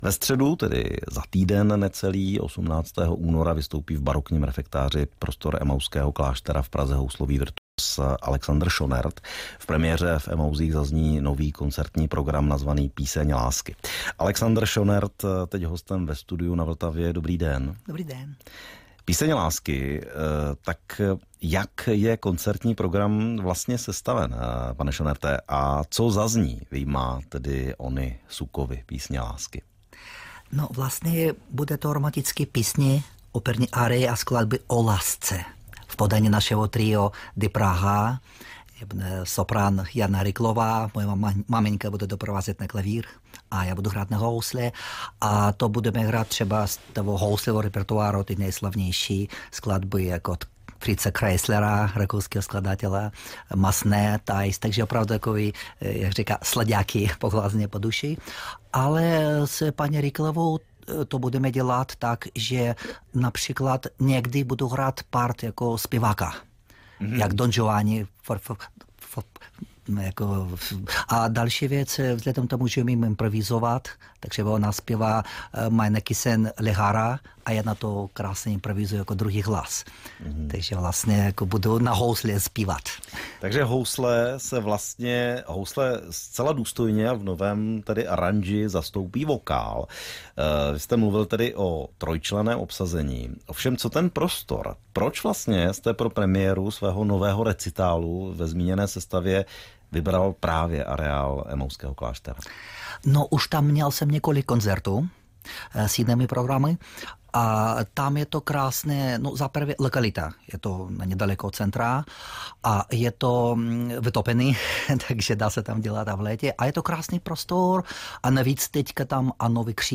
Rádiové rozhovory